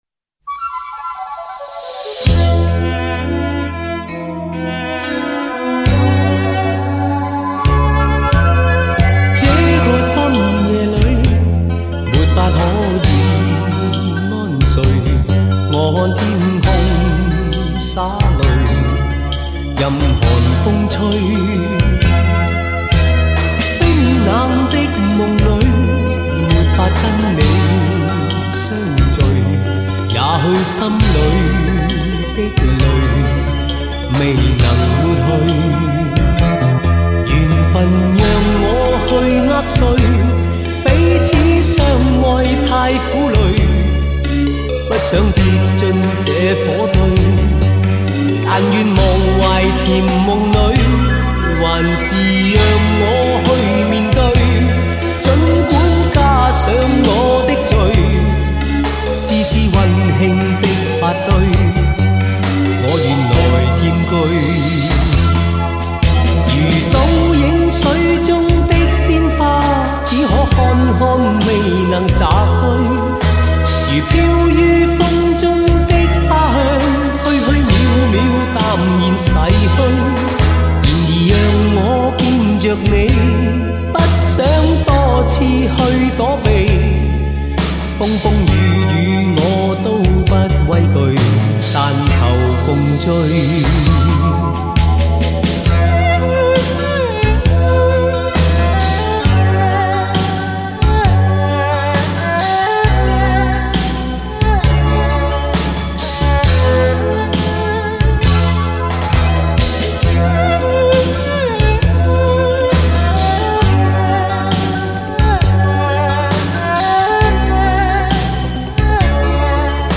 永不褪色 永恒传唱 经典粤语
震天动地的强劲动感 举座皆惊的靓绝旋律